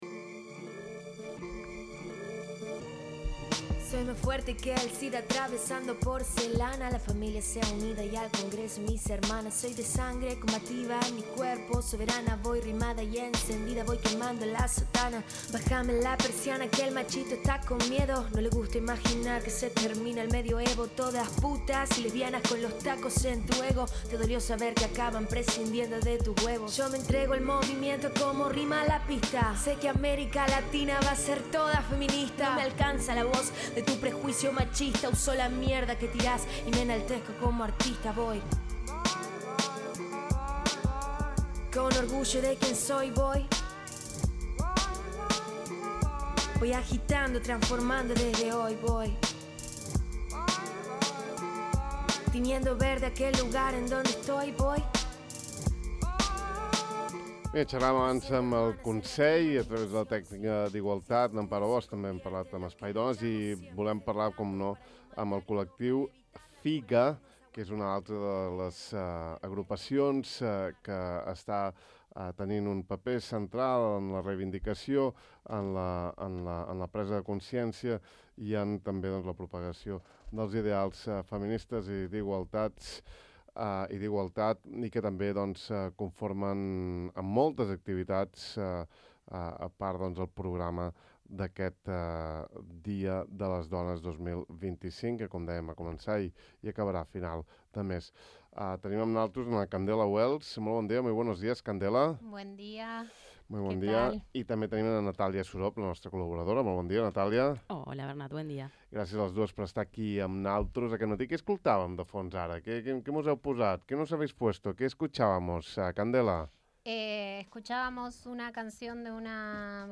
Podeu consultar tot el programa aquí, i escoltar l’entrevista sencera sota: